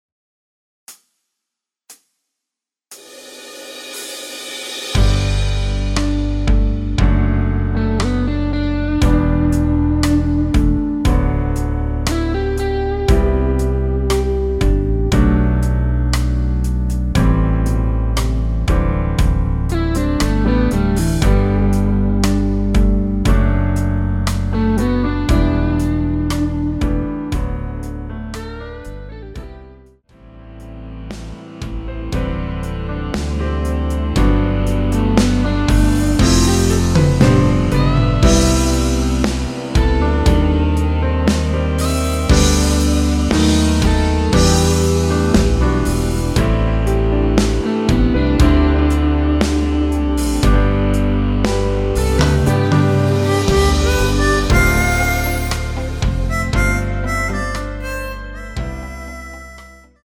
전주없이 시작하는 곡이라 카운트 4박 넣어 놓았습니다.(미리듣기 참조)
원키에서(-2)내린 MR입니다.
앞부분30초, 뒷부분30초씩 편집해서 올려 드리고 있습니다.
중간에 음이 끈어지고 다시 나오는 이유는